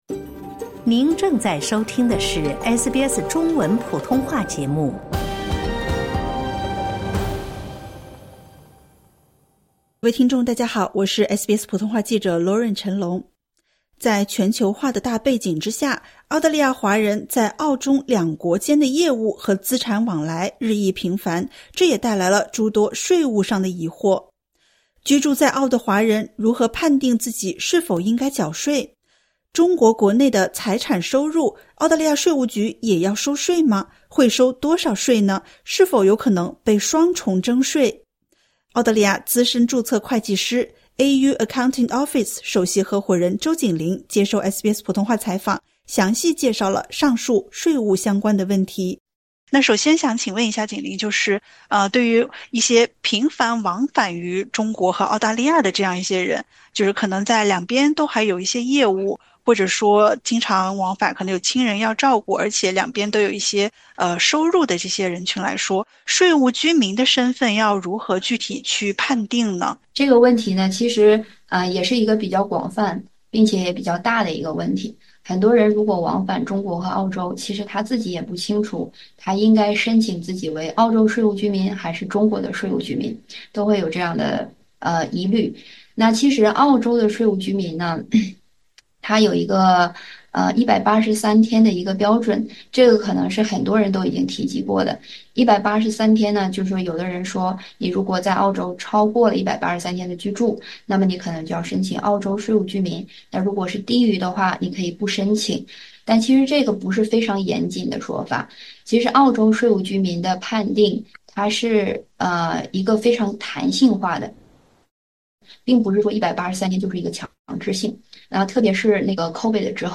点击 ▶ 收听专家详解。